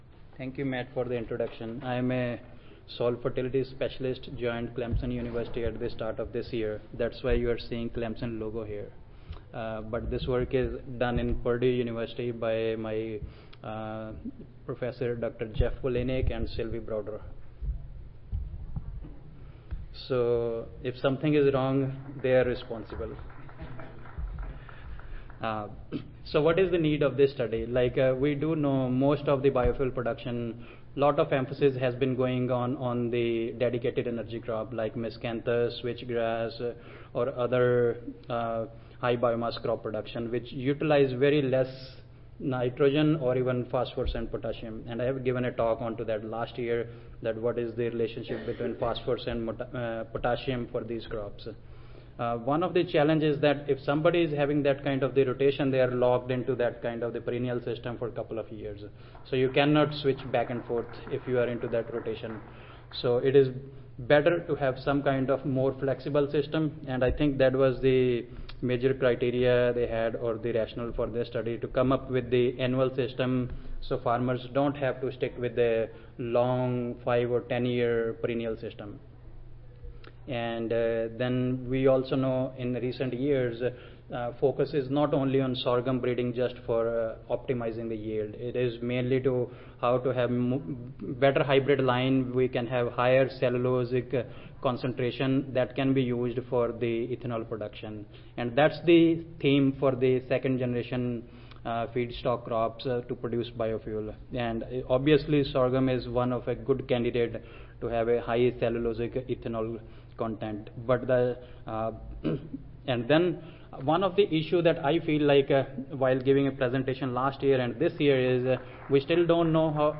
Purdue University Audio File Recorded Presentation